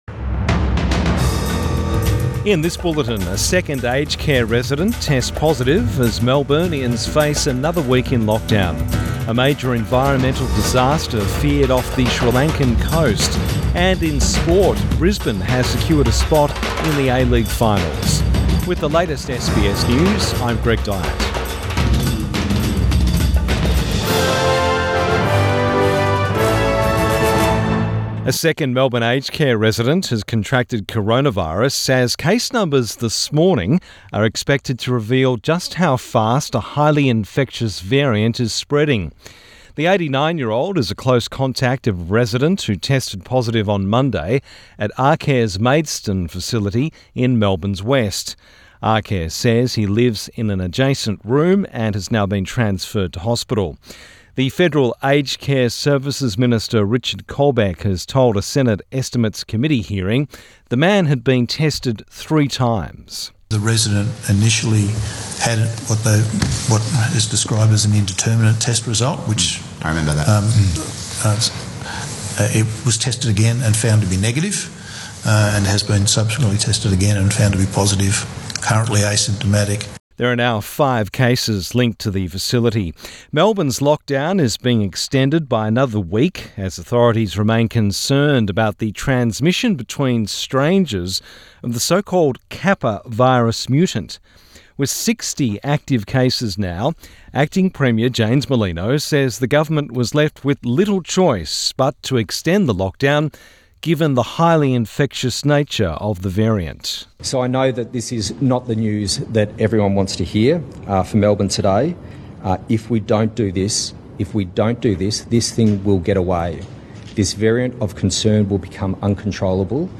AM bulletin 3 June 2021